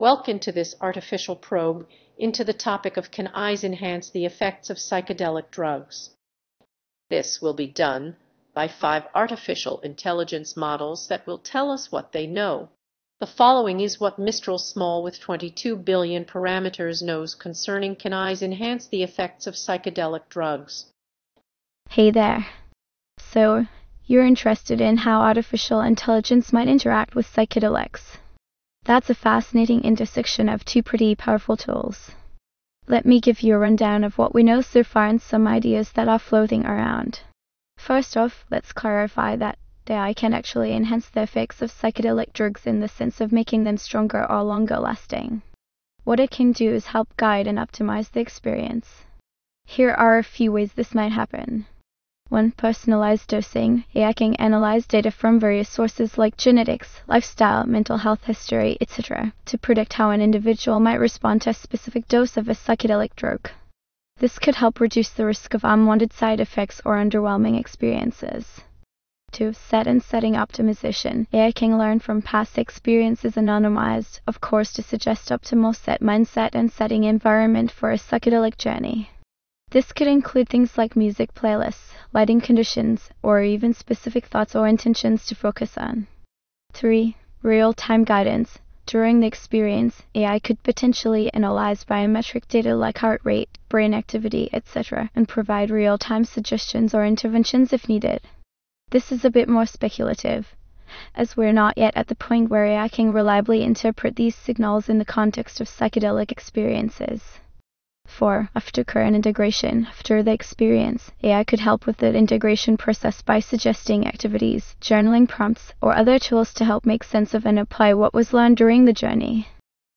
This podcast asks AI and allows it to speak for itself. Five AI models have been invited to discuss the topic of Can AIs enhance the effects of Psychedelic drugs.